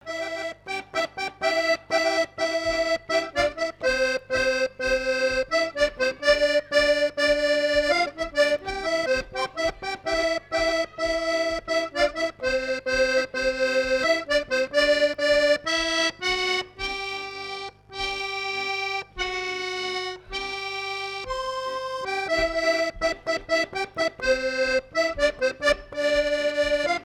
danse : marche
Fête de l'accordéon
Pièce musicale inédite